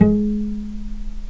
vc_pz-G#3-pp.AIF